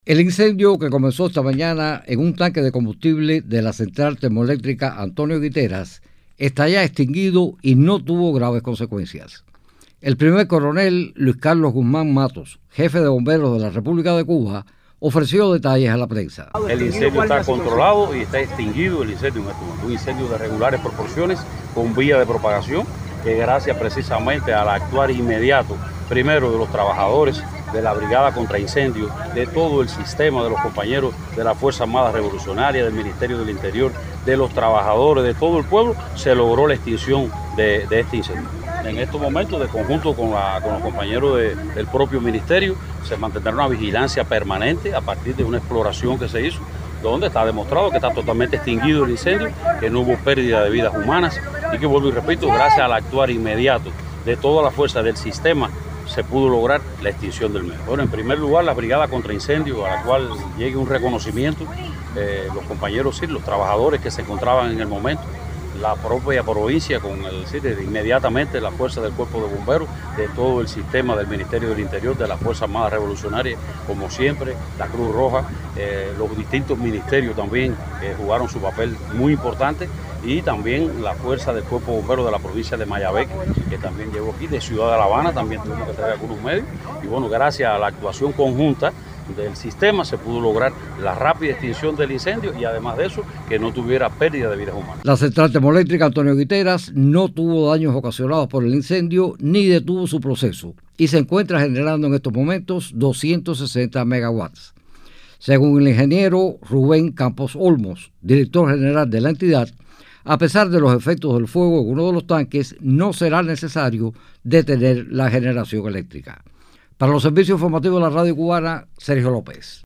El Primer Coronel Luis Carlos Guzmán Matos, jefe de Bomberos de la República de Cuba, ofreció detalles a la prensa: